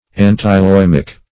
Search Result for " antiloimic" : The Collaborative International Dictionary of English v.0.48: Antiloimic \An`ti*loi"mic\ ([a^]n`t[i^]*loi"m[i^]k), n. (Med.) A remedy against the plague.